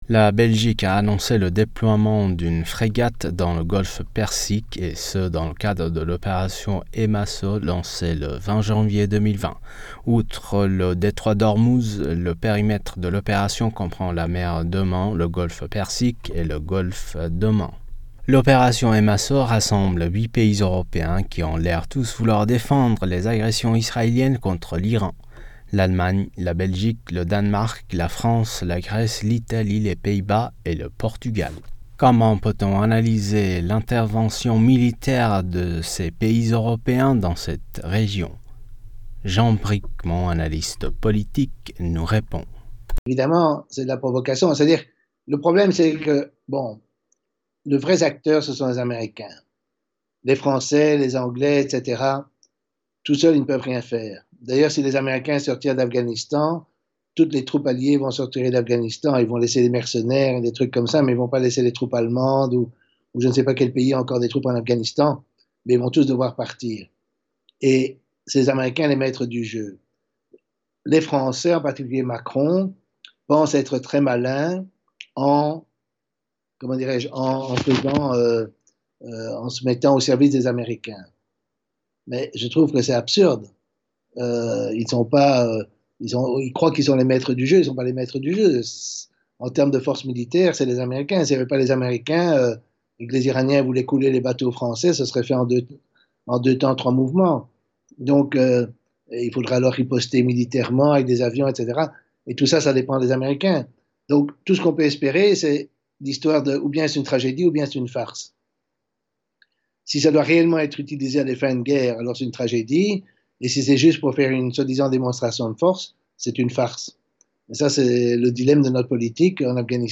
Comment analyser cette intervention militaire? Décryptage avec Jean Bricmont, Analyste politique.
interview